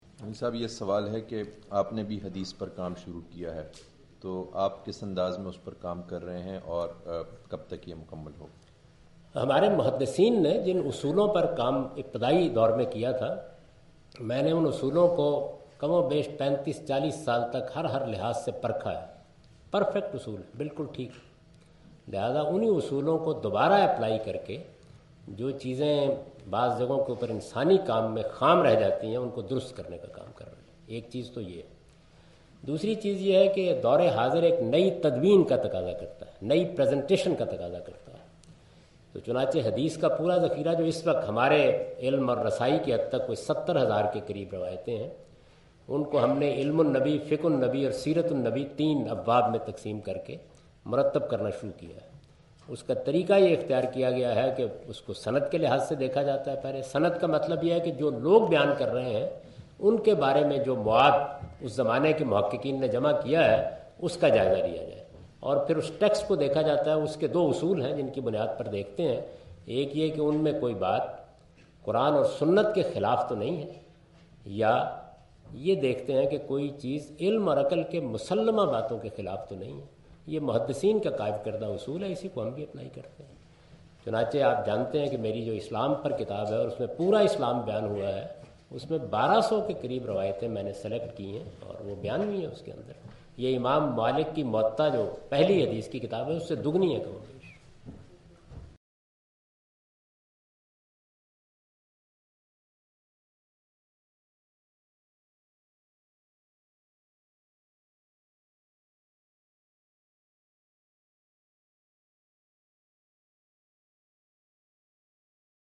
Javed Ahmad Ghamidi answer the question about "Hadith Project of Al-Mawrid" during his visit to Queen Mary University of London UK in March 13, 2016.
جاوید احمد صاحب غامدی اپنے دورہ برطانیہ 2016 کےدوران کوئین میری یونیورسٹی اف لندن میں "المورد کا حدیث پراجیکٹ" سے متعلق ایک سوال کا جواب دے رہے ہیں۔